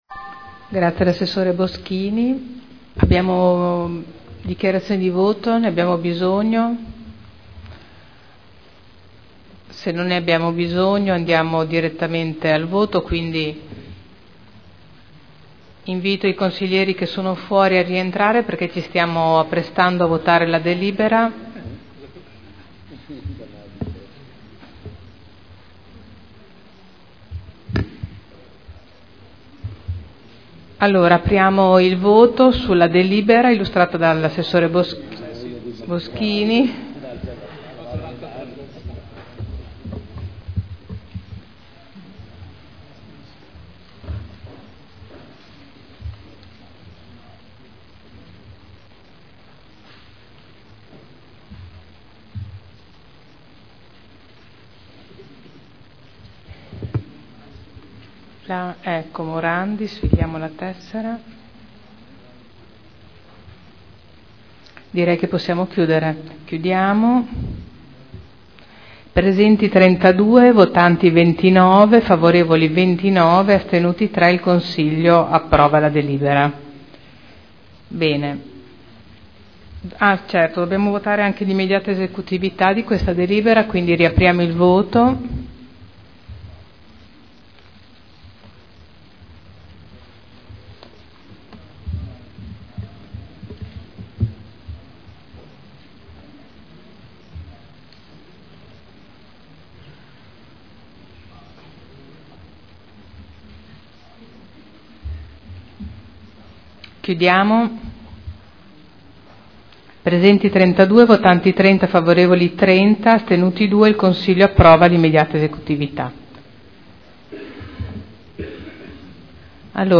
Presidente — Sito Audio Consiglio Comunale
Seduta del 12/11/2012 Mette ai voti la delibera.